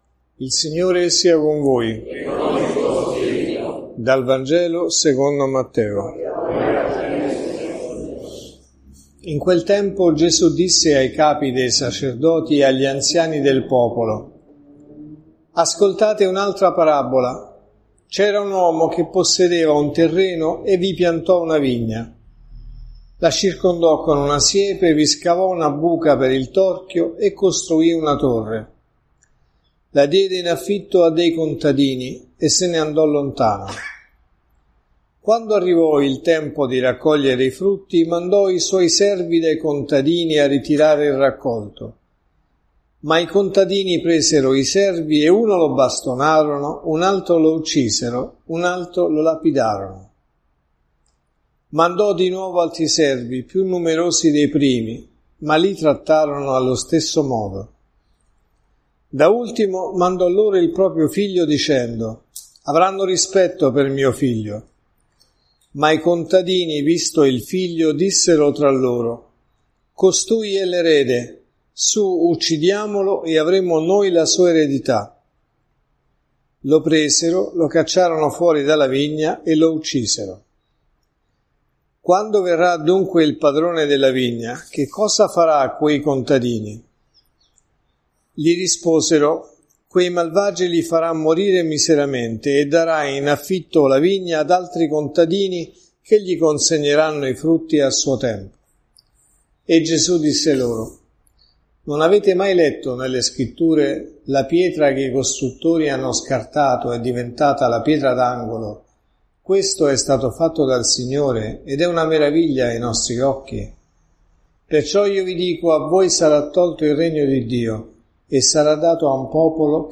Omelie